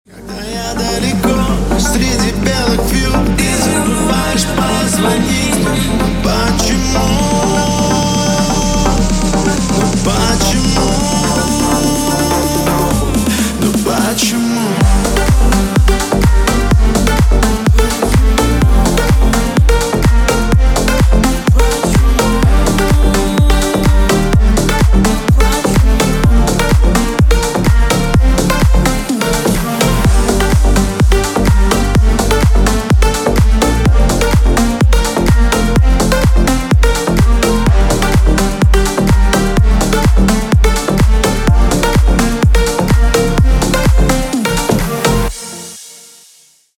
• Качество: 320, Stereo
мужской голос
Club House
ремиксы